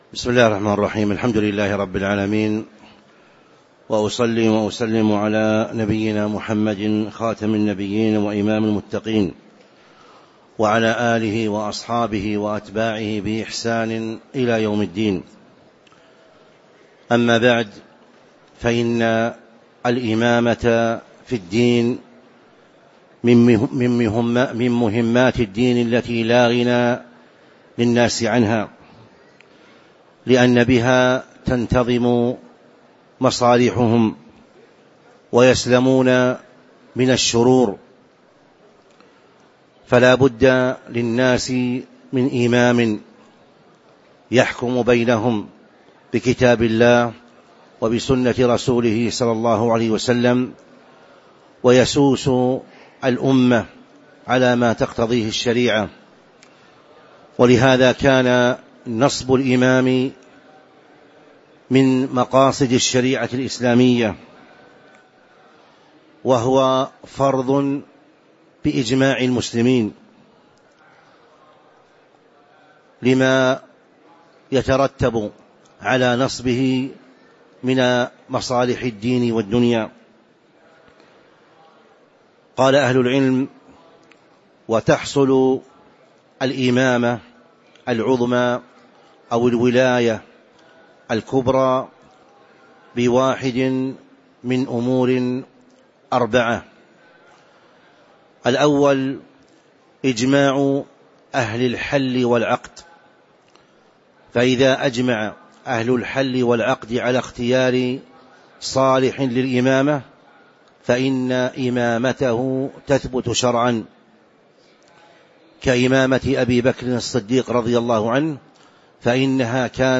تاريخ النشر ٨ ربيع الأول ١٤٤٥ هـ المكان: المسجد النبوي الشيخ